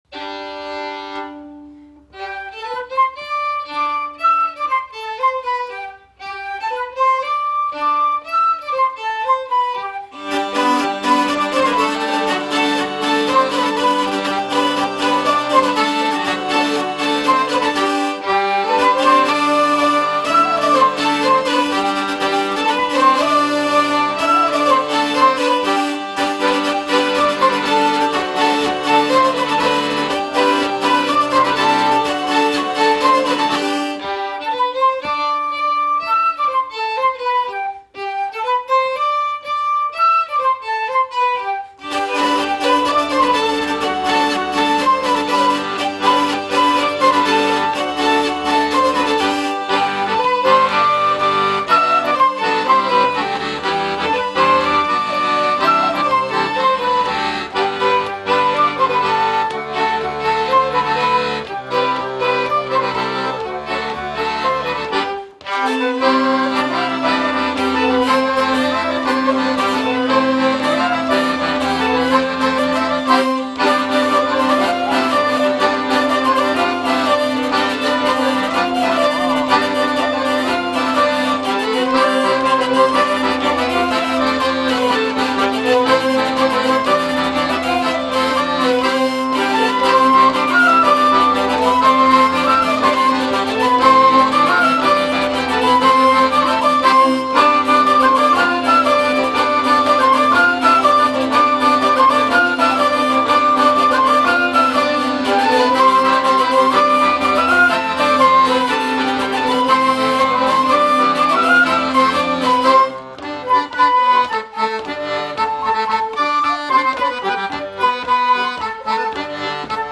Répétition du 23/06/2012 - Spectacle de Reims - Musique